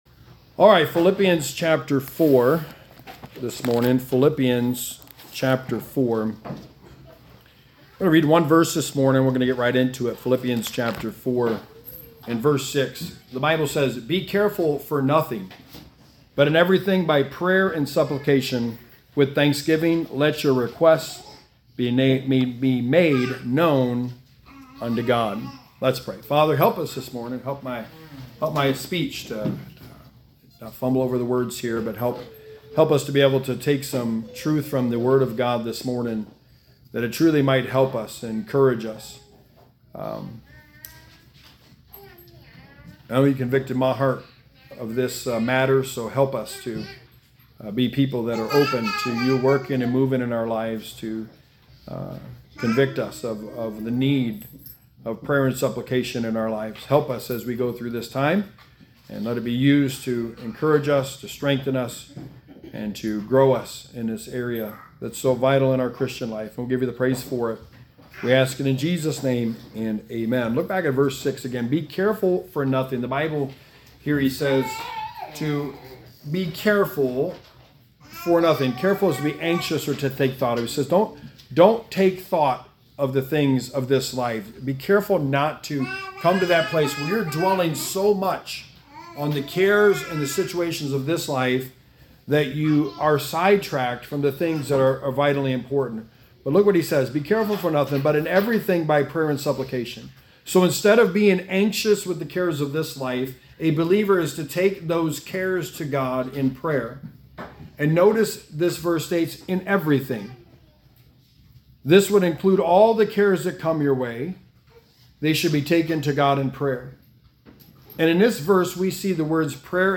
Sermon 25: The Book of Philippians: Prayer & Supplication
Service Type: Sunday Morning